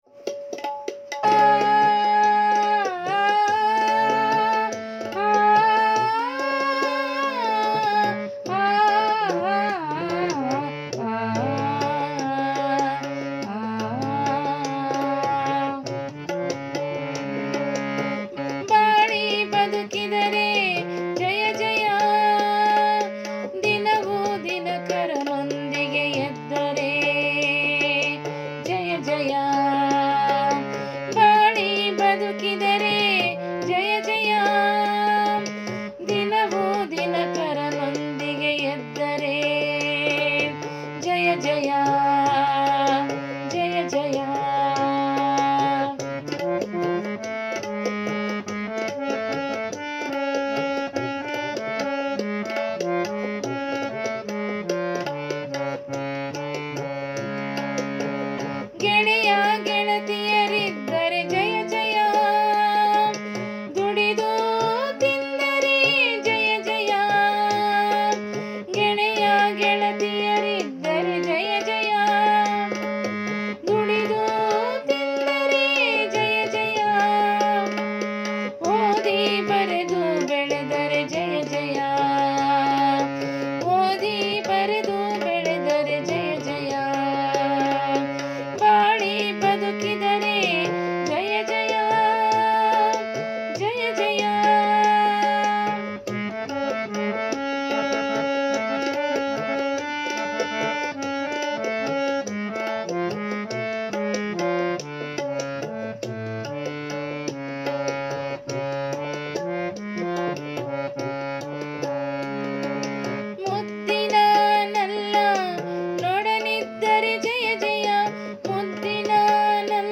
ರಾಗ ಸಂಯೋಜನೆ-ಗಾಯನ